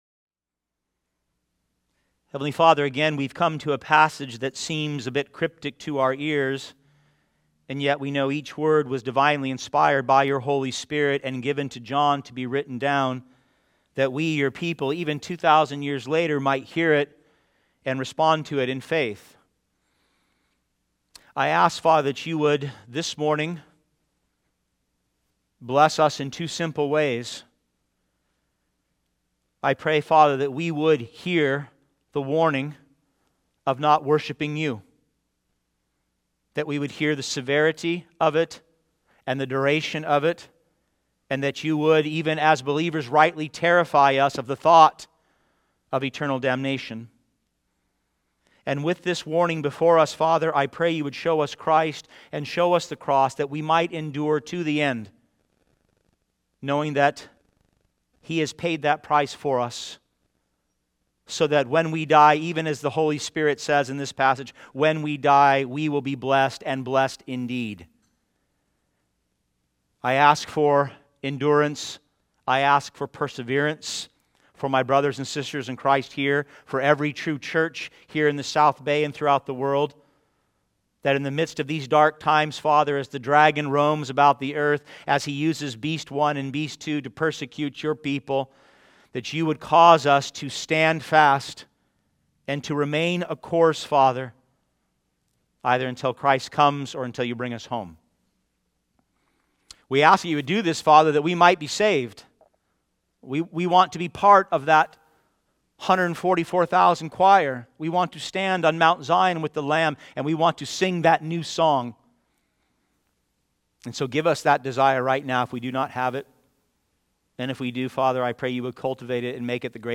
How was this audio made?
Heed The Warning - Revelation 14:6-13 | Christ Community Church of San Jose